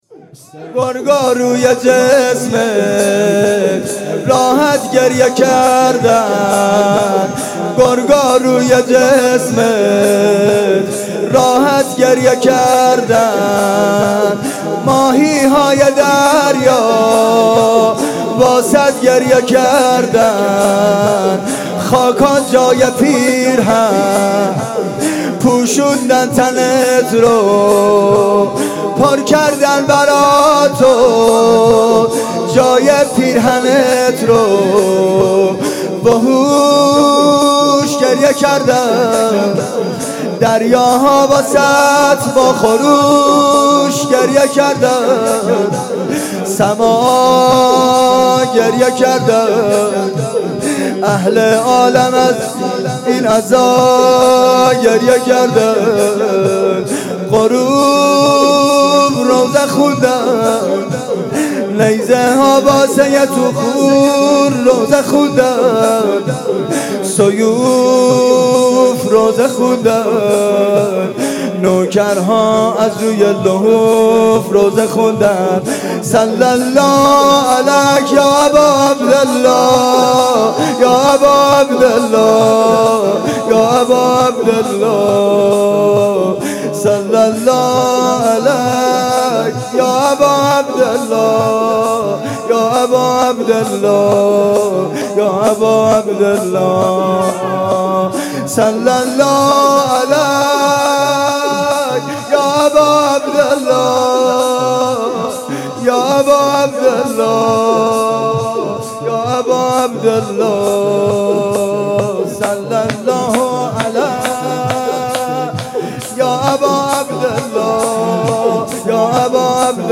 شور (گرگا روی جسمت